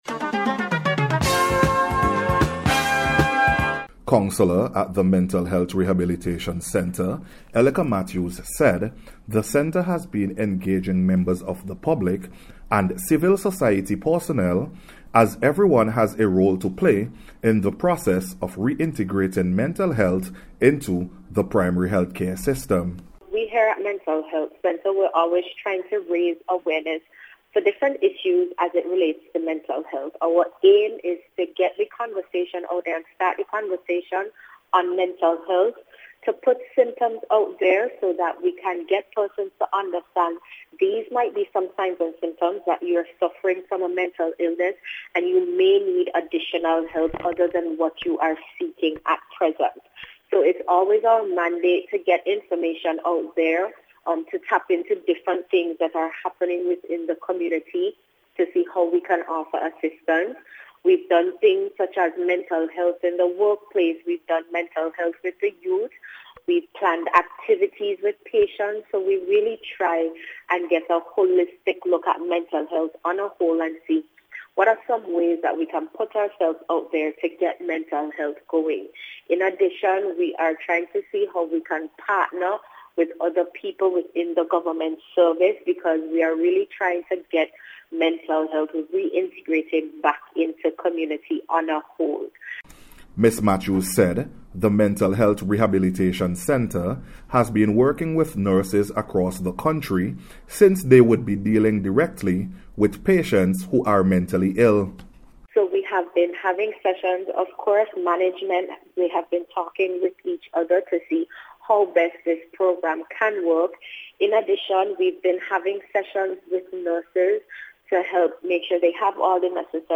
NBC’s Special Report for April 4th 2022
MENTAL-HEALTH-REINTEGRATION-REPORT.mp3